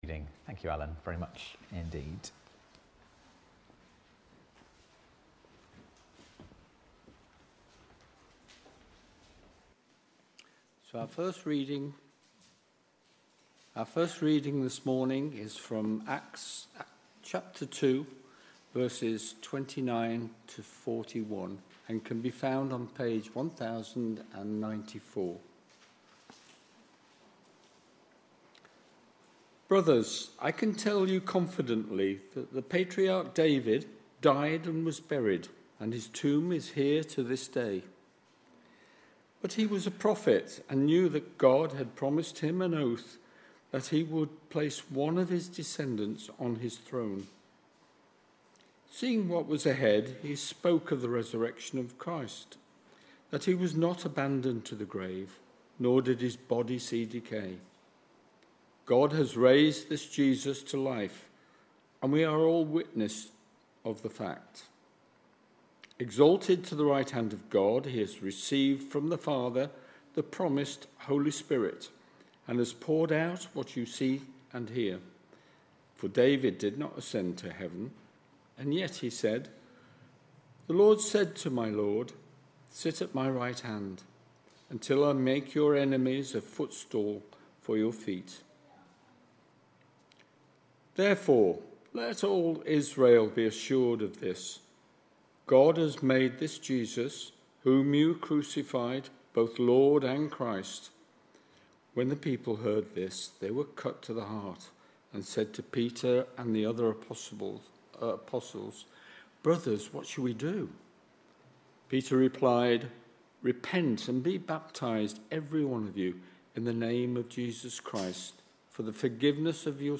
Readings-Sermon-on-7th-September-2025.mp3